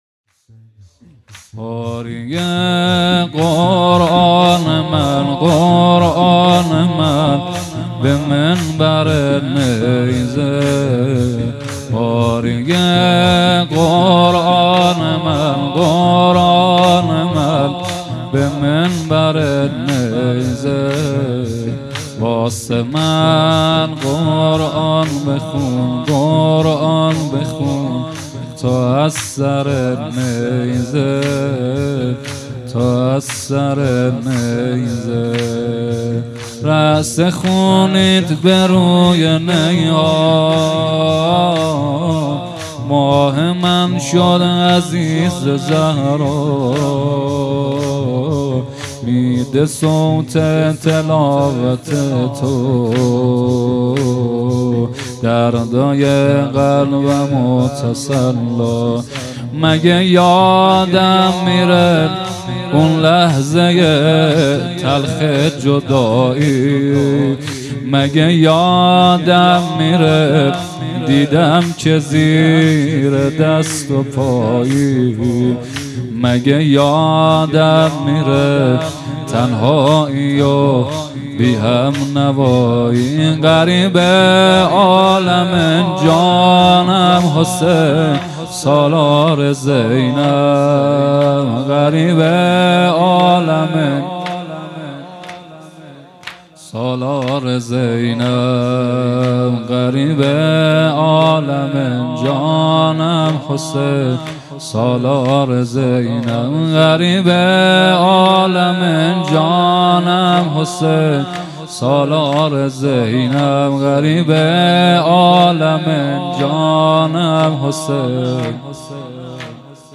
خیمه گاه - هیئت رزمندگان اسلام (ثارالله) - زمینه | قاری قرآن من به منبر نیزه
هیئت رزمندگان اسلام (ثارالله)